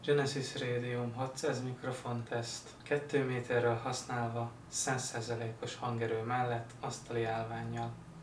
Hangminőség teszt: távolság tesztek
2 méter távolság, 100%